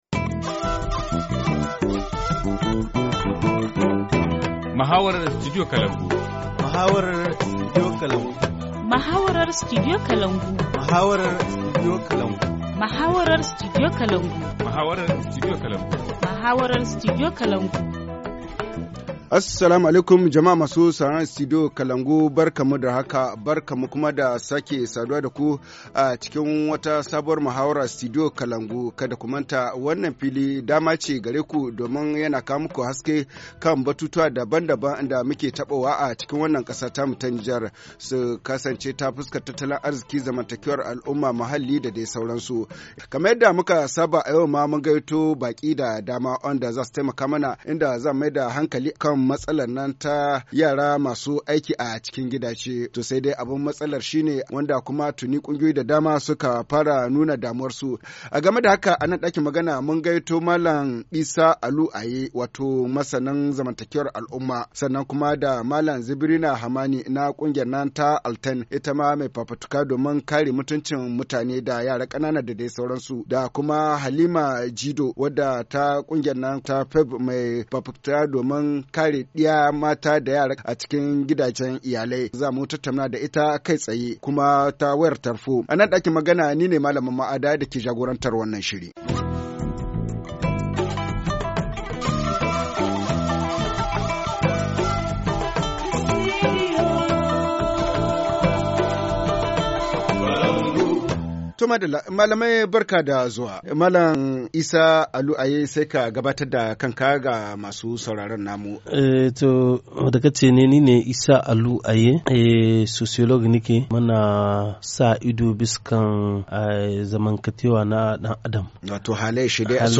Forum Haoussa 24/03/2018 : Violences faites aux femmes au Niger : cas du travail des jeunes filles domestiques - Studio Kalangou - Au rythme du Niger